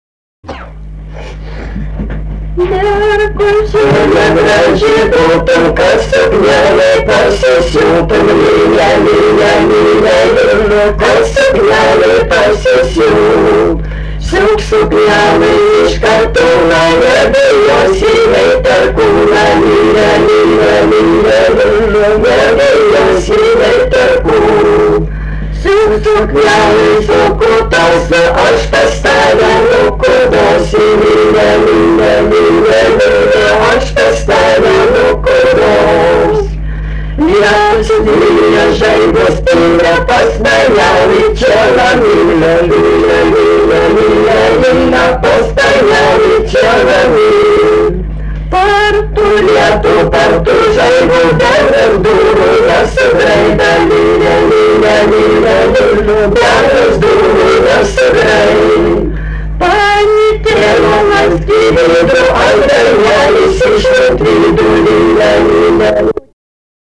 daina
žaidimai ir rateliai